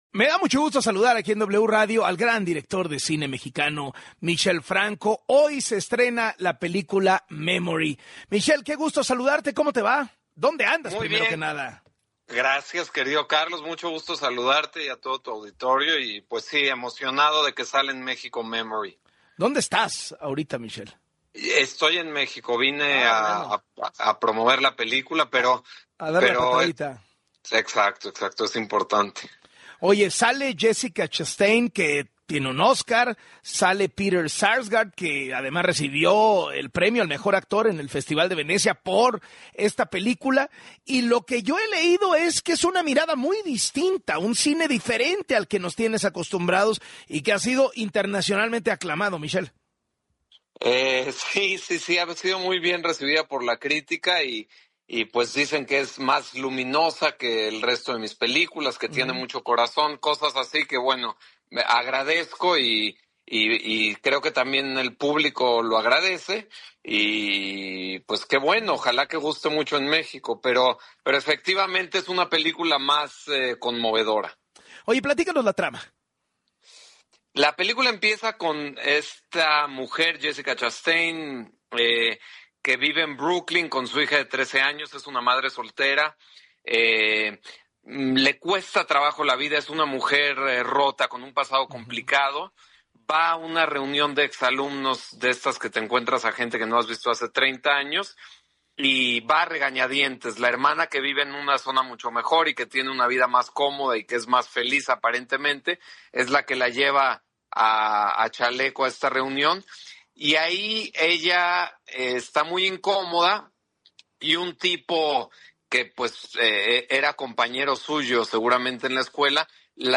En entrevista con Carlos Loret de Mola habló un poco sobre la trama y dijo que “tiene más corazón” y es más conmovedora que el resto de sus filmes.